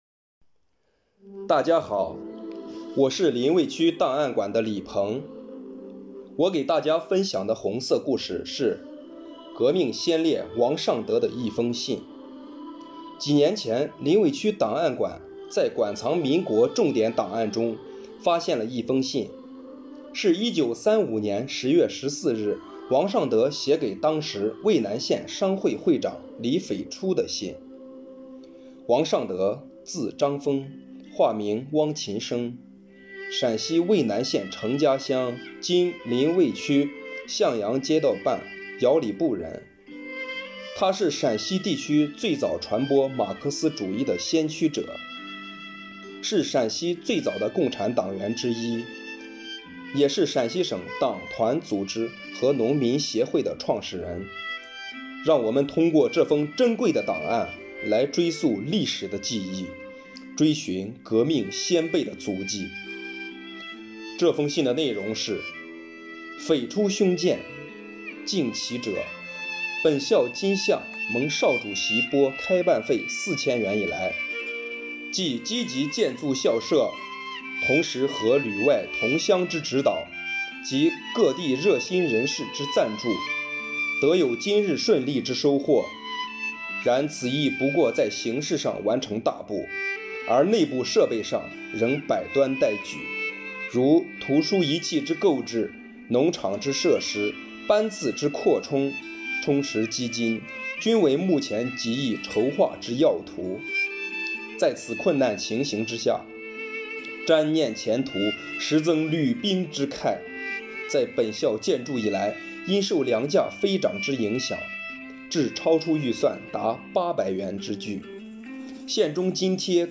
【红色档案诵读展播】革命先烈王尚德的一封信